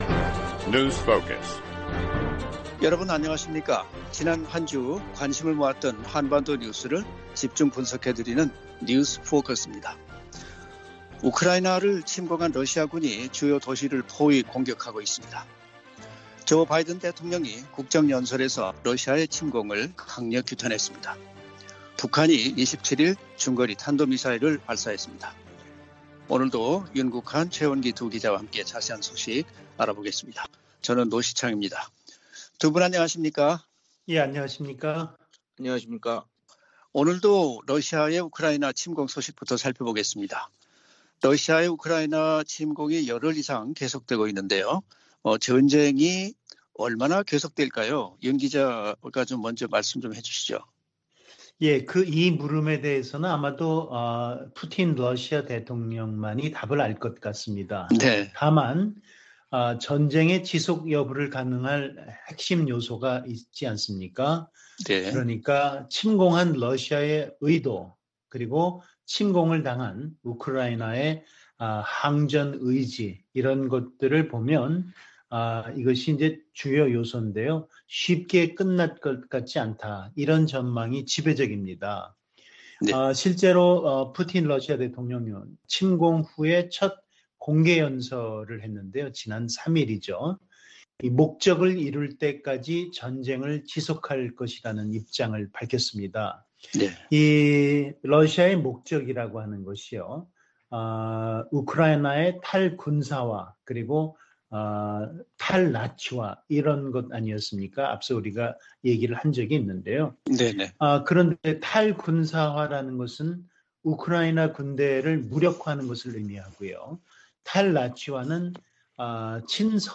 VOA 한국어 방송의 월요일 오전 프로그램 2부입니다.